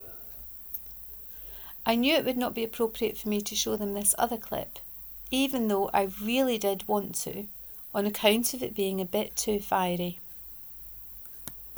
Shoving yer Grannie aff a bus 2 : reading of this post